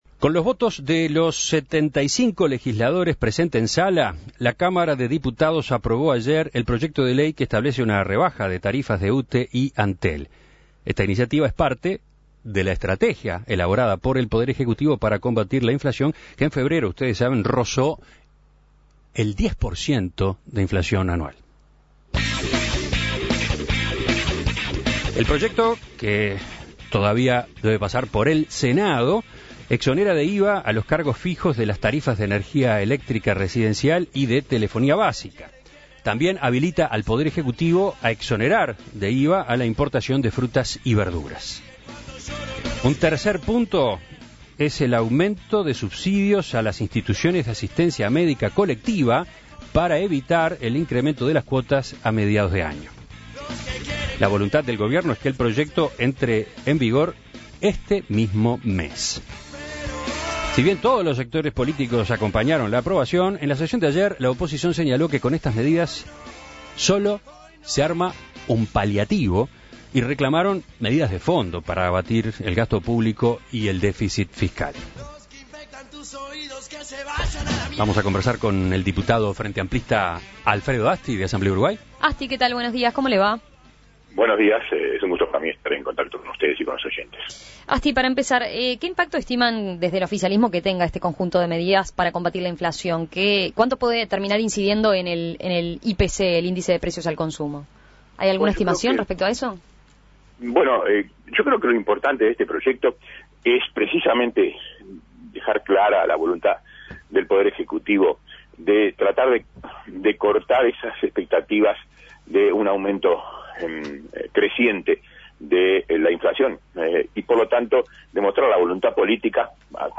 En Perspectiva consultó al diputado de Asamblea Uruguay Alfredo Asti, quien destacó que la voluntad política de esta medida es desalentar las expectativas de crecimiento de la inflación entre la población.